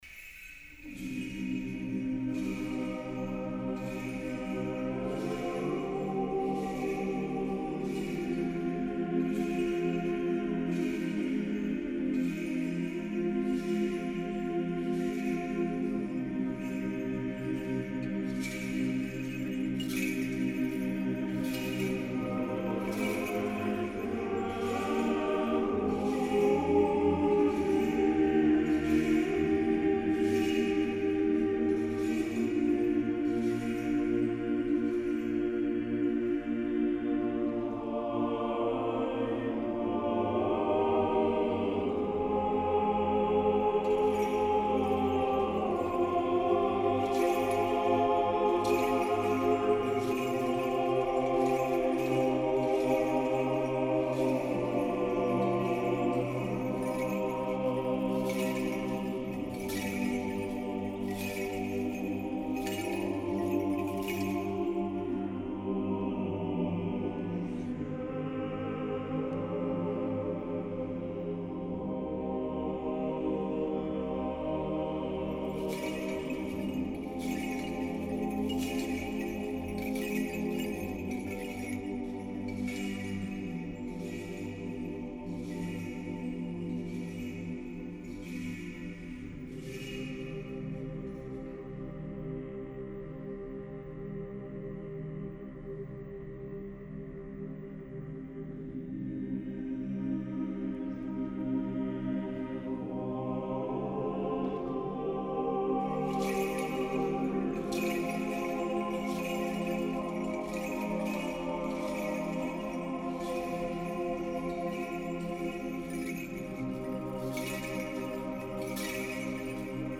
Хор Сретенского монастыря.
Божественная литургия.
12-Cherubic-Hymn.mp3